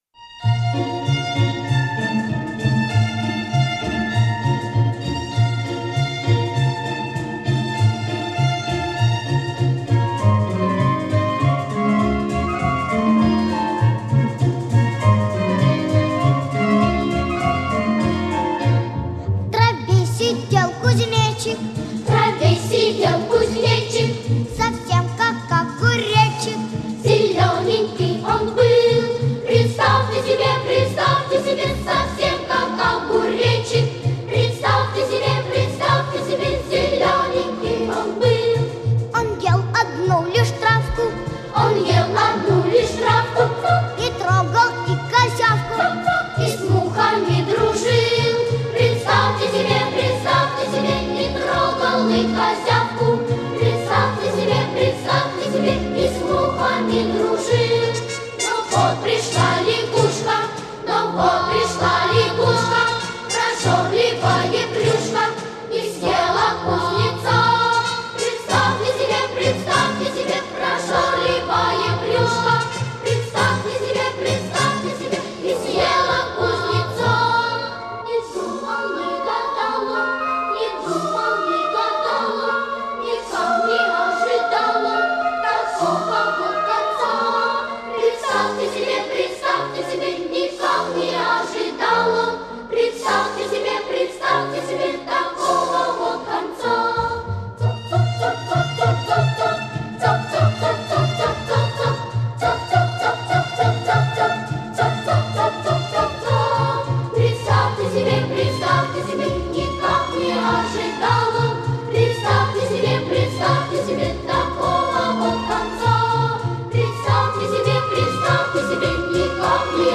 это самая первая и самая популярная детская песенка.